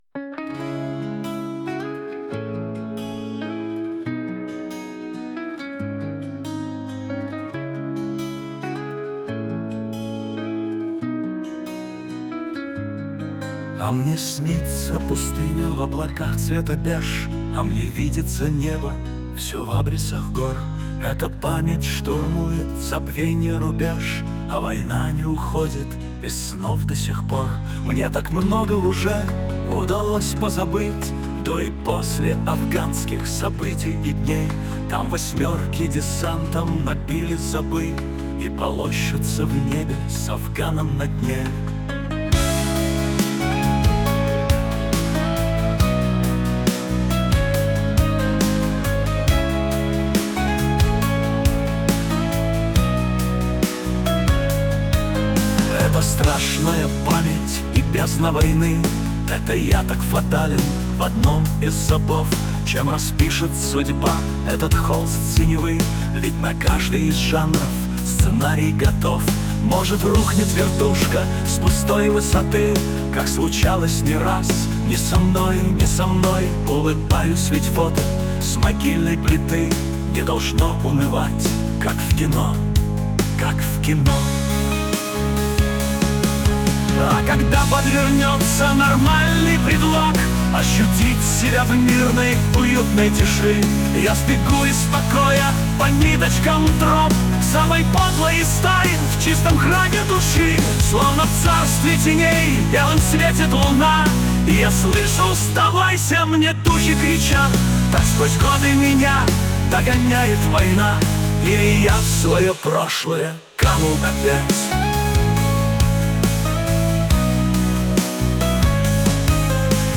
pustynja.mp3 (3753k) Попытка песни ИИ